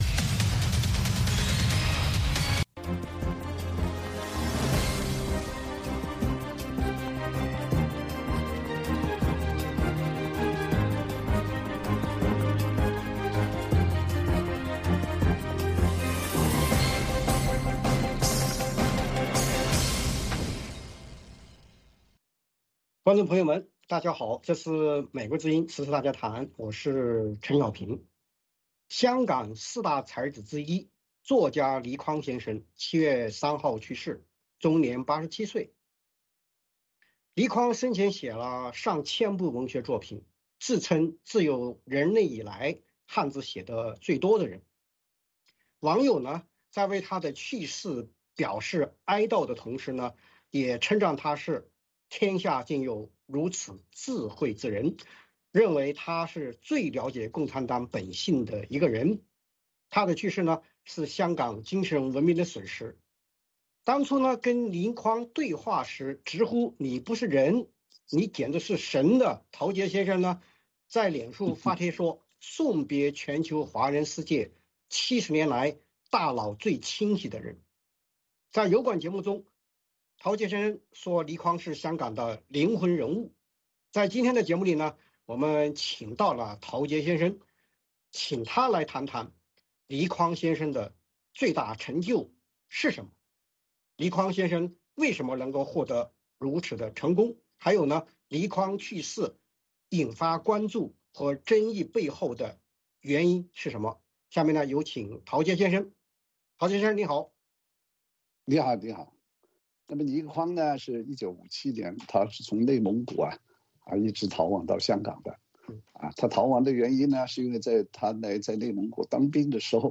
《时事大家谈》围绕重大事件、热点问题、区域冲突以及中国内政外交的重要方面，邀请专家和听众、观众进行现场对话和讨论，利用这个平台自由交换看法，探索事实。